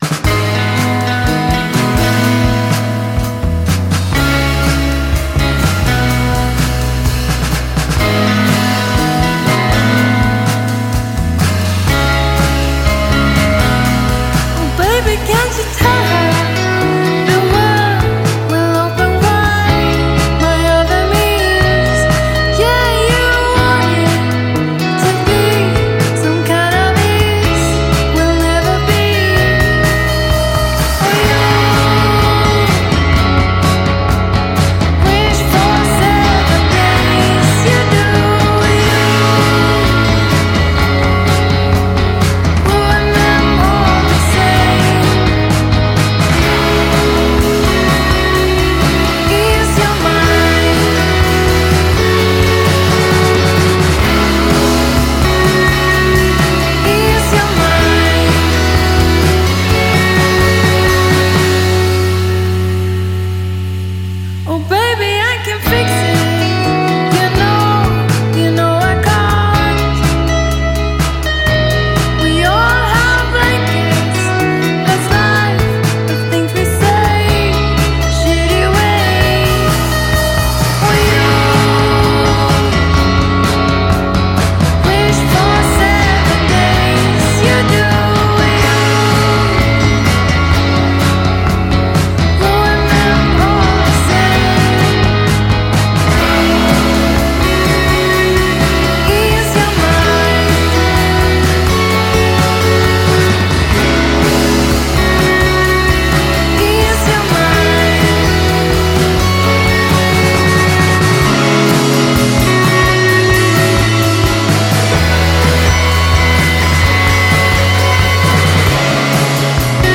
herzzerreißenden